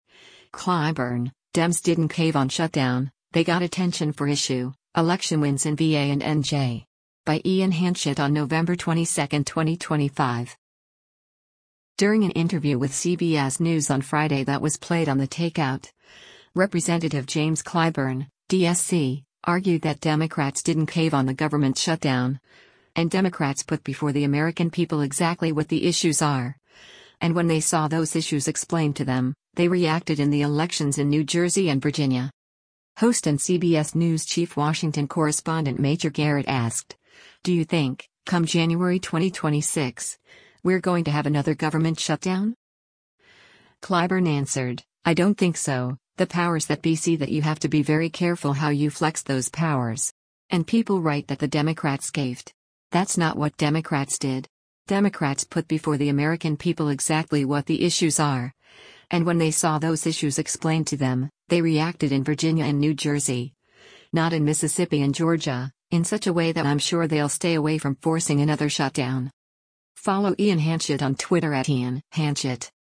During an interview with CBS News on Friday that was played on “The Takeout,” Rep. James Clyburn (D-SC) argued that Democrats didn’t cave on the government shutdown, and “Democrats put before the American people exactly what the issues are, and when they saw those issues explained to them, they reacted” in the elections in New Jersey and Virginia.
Host and CBS News Chief Washington Correspondent Major Garrett asked, “Do you think, come January 2026, we’re going to have another government shutdown?”